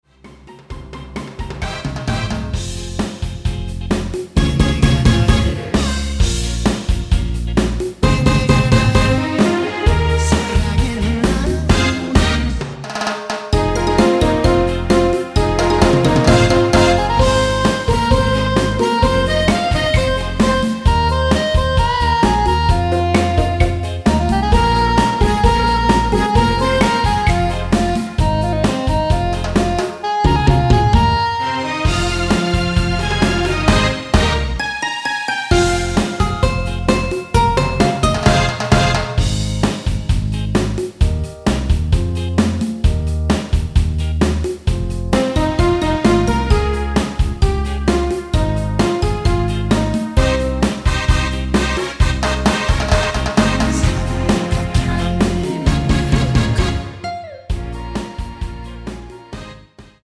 원키 코러스 포함된 MR 입니다.
Fm
앞부분30초, 뒷부분30초씩 편집해서 올려 드리고 있습니다.
중간에 음이 끈어지고 다시 나오는 이유는